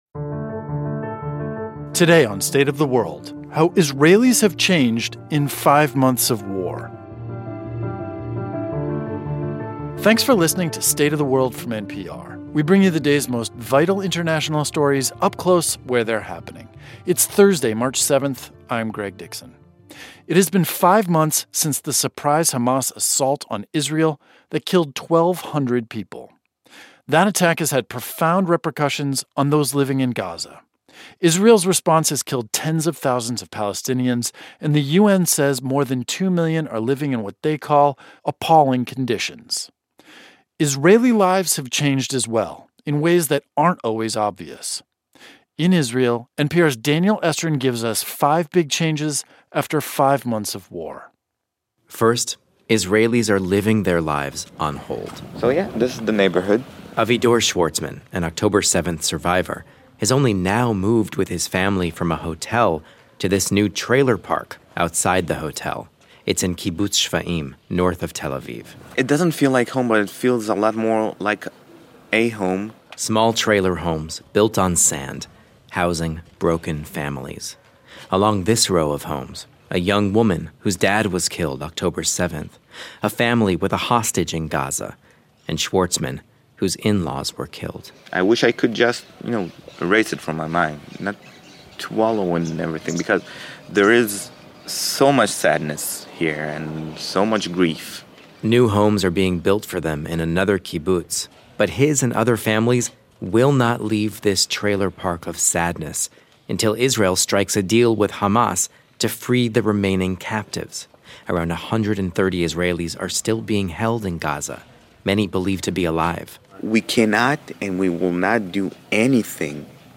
It has been five months since the October 7th Hamas attack that was the single deadliest day for Israelis in history, prompting the deadly Israeli assault on Gaza. Our reporter in Israel brings us five ways Israelis have been changed through five months of war.